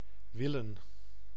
Ääntäminen
Synonyymit rekenen Ääntäminen : IPA: [ʋɪ.lǝː(n)] Tuntematon aksentti: IPA: /ˈʋɪl.ə(n)/ Haettu sana löytyi näillä lähdekielillä: hollanti Käännös Ääninäyte Verbit 1. want UK US 2. wish US 3. mean US Esimerkit Ik wou dat ik dat kon doen.